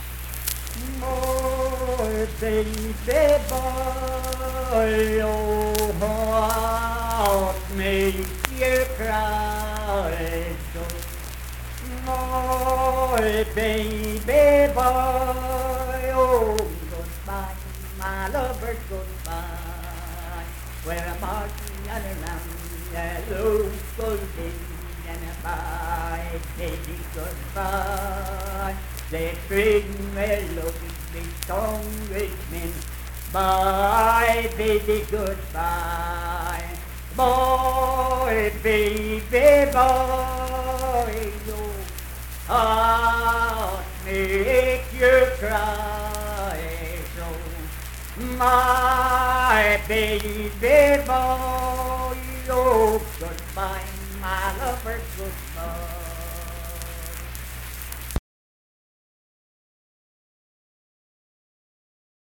Unaccompanied vocal music performance
Children's Songs
Voice (sung)